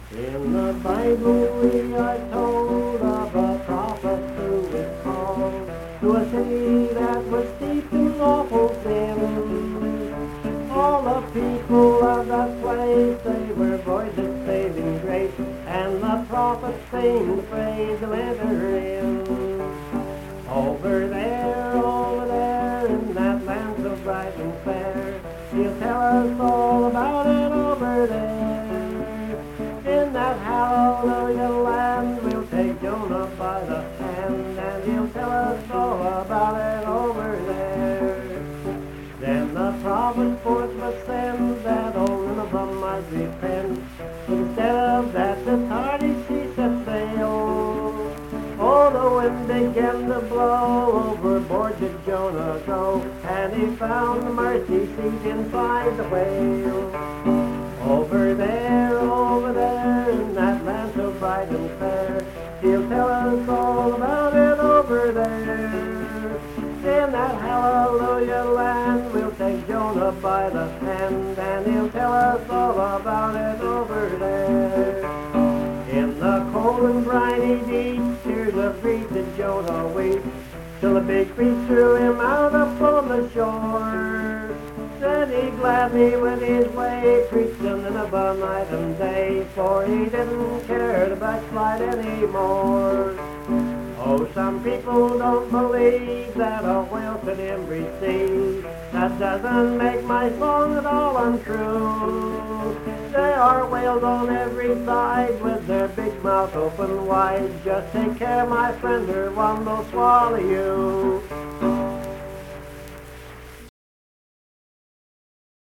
Accompanied vocal and guitar music
Verse-refrain 4(4)&R(4).
Performed in Hundred, Wetzel County, WV.
Hymns and Spiritual Music
Voice (sung), Guitar